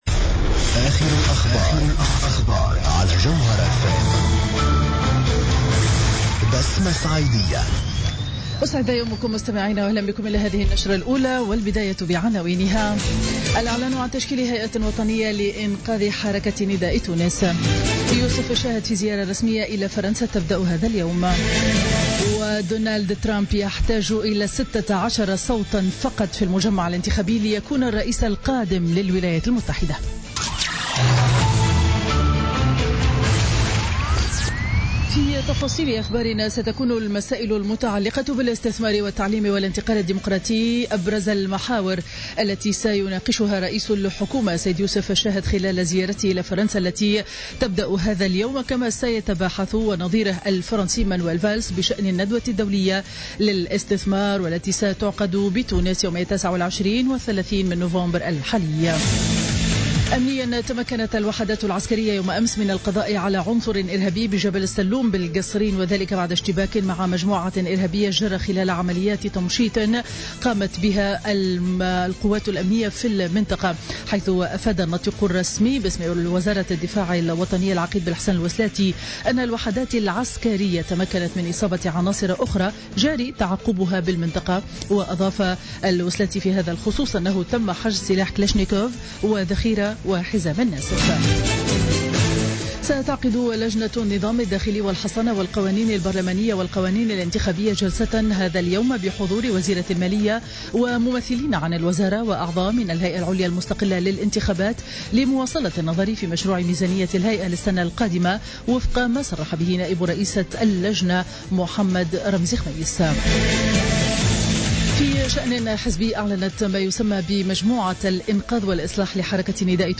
نشرة أخبار السابعة صباحا ليوم الاربعاء 9 نوفمبر 2016